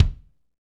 Index of /90_sSampleCDs/Northstar - Drumscapes Roland/KIK_Kicks/KIK_Funk Kicks x
KIK FNK K04L.wav